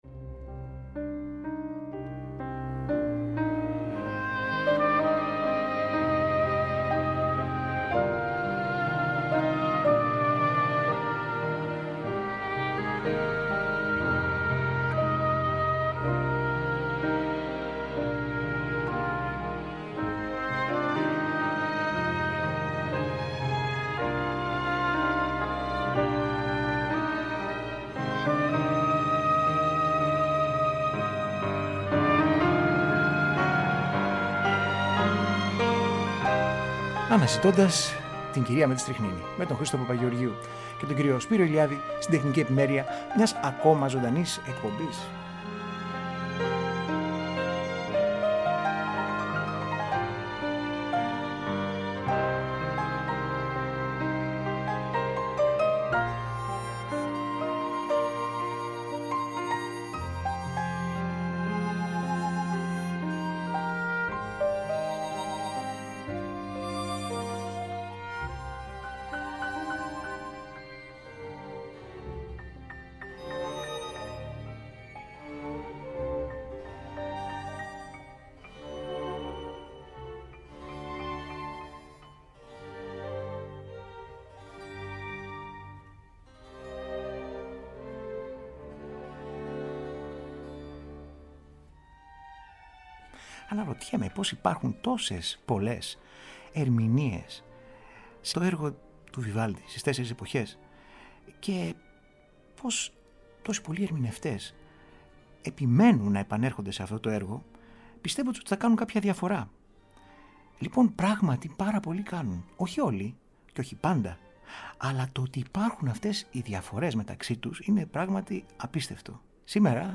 Το «Καλοκαίρι» του Vivaldi σε ανεπανάληπτες ερμηνείες
Συγκριτικές Ακροάσεις